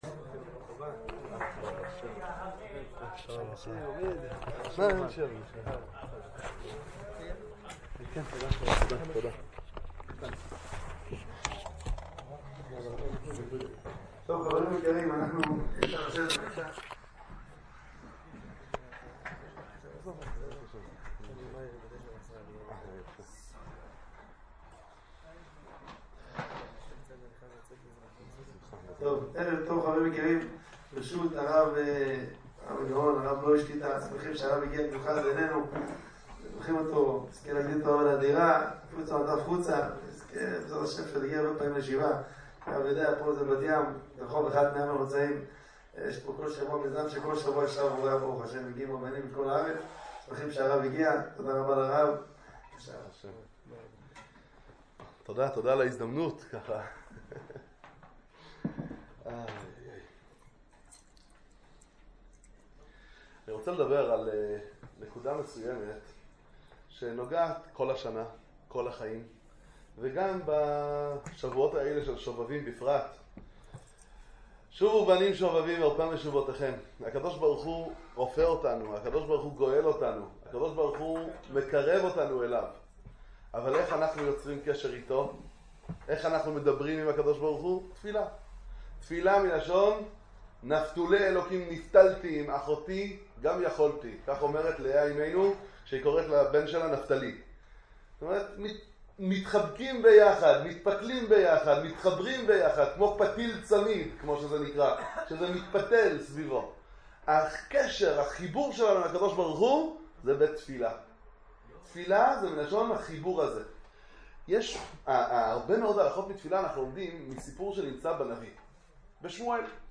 חיזוק לתפילה - הרצאה בעיר בת ים